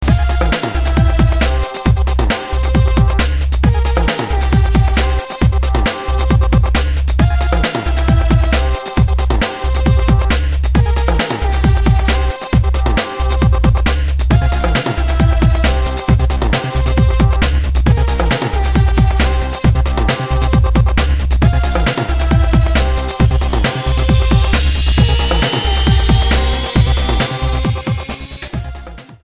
STYLE: Dance/Electronic
"big-beat"